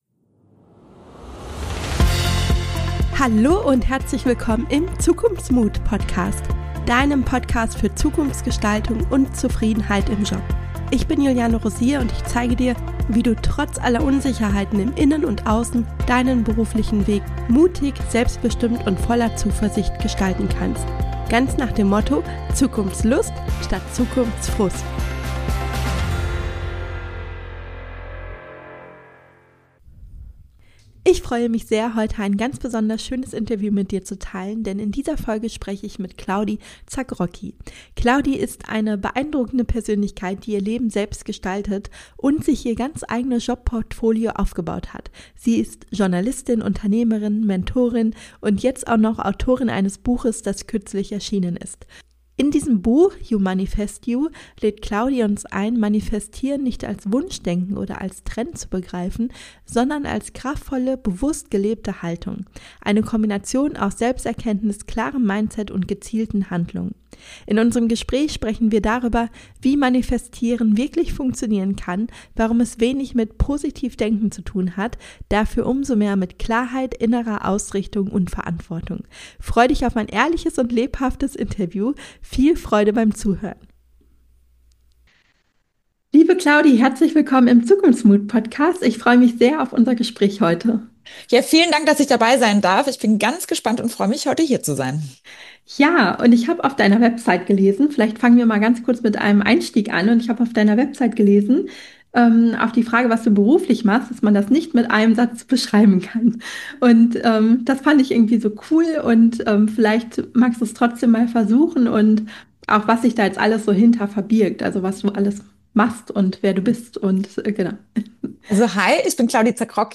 Wir sprechen darüber, was Manifestieren wirklich bedeutet, wie du diese Haltung für dich nutzbar machen kannst und warum es dabei weniger um positives Denken geht, sondern viel mehr um Verantwortung, Klarheit und ein tiefes Verständnis für die eigene Zukunftsvision. Freu dich auf ein lebendiges, ehrliches und kraftvolles Interview, das dich einlädt, deine eigene Zukunft aktiv zu gestalten.